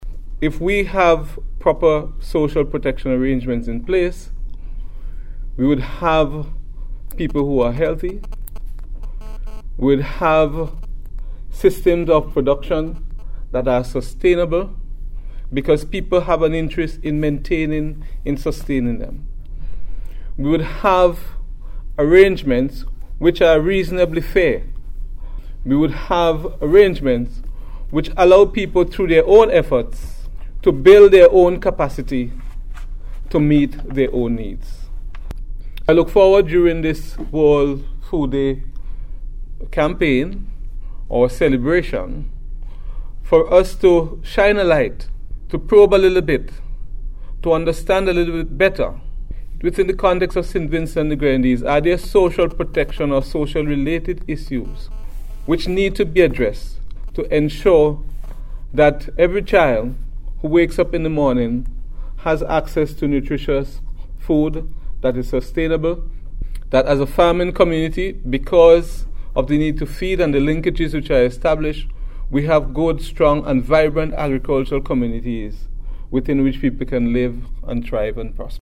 Mr. Caine was speaking at the launch of the World Food Day programme for 2015, held at the National Public Library yesterday.